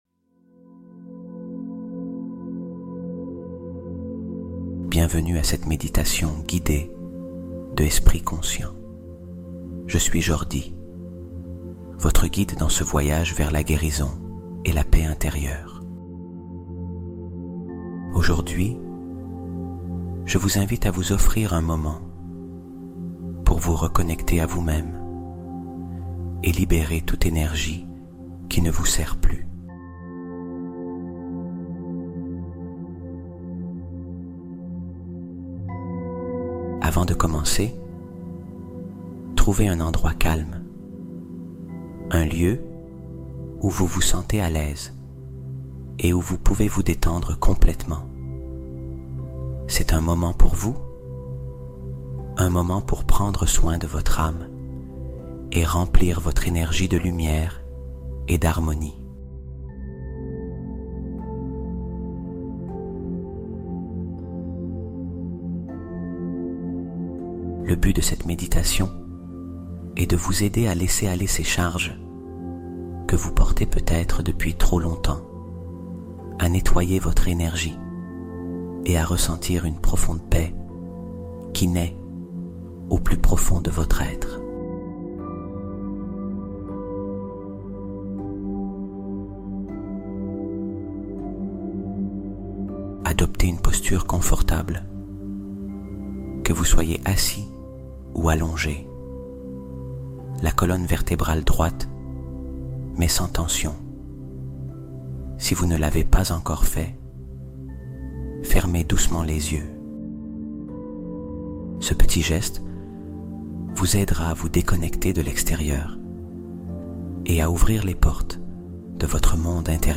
10 Minutes Pour Guérir Ton Âme Complètement : Méditation de Nettoyage Énergétique Profond